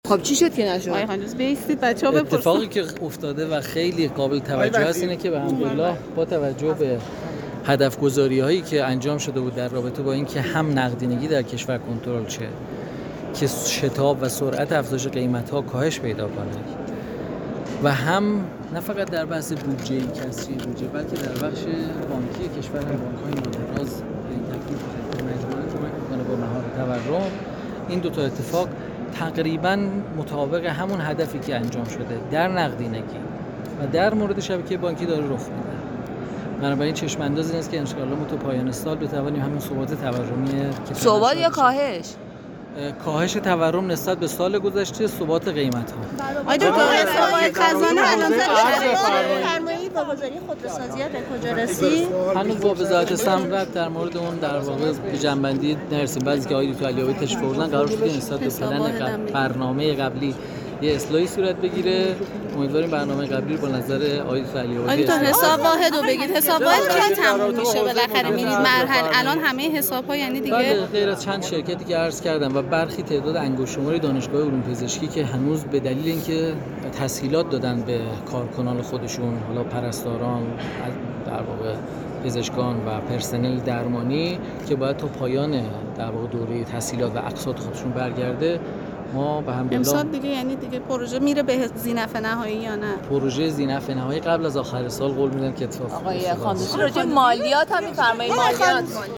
به گزارش گروه اقتصاد خبرگزاری علم و فناوری آنا، سید احسان خاندوزی وزیر اقتصاد در حاشیه همایش بزرگداشت از حسابداران و حسابرسان در جمع خبرنگاران توضیحاتی در خصوص کنترل تورم داد و گفت: با اقدامات و سیاست‌گذاری‌هایی که در حوزه کنترل نقدینگی و سامان‌دهی بانک‌ها و موسسات مالی شده است، پیش بینی می‌کنیم که تا پایان سال جاری نسبت به سال قبل کاهش تورم و همچنین ثبات قیمت‌ها را شاهد باشیم.